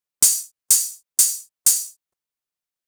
HIHAT011_DISCO_125_X_SC3.wav
1 channel